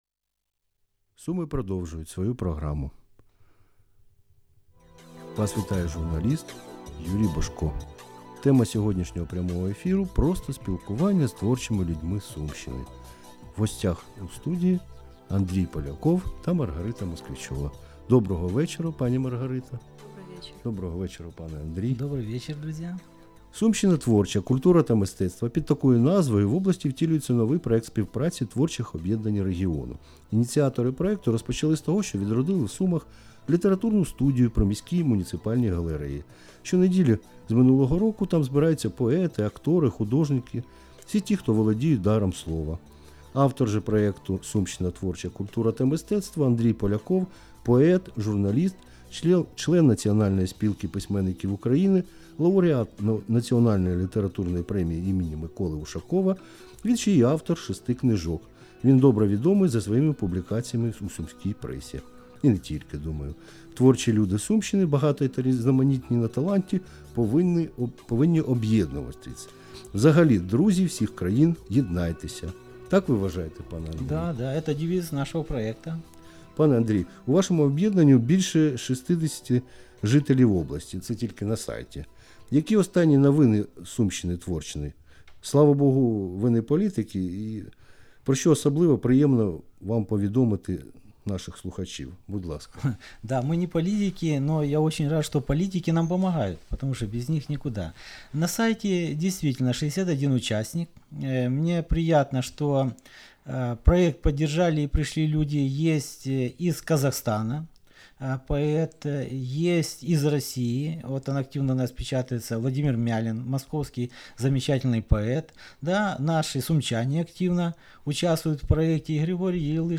Проект "Сумщина творческая" на областном радио (запись с эфира)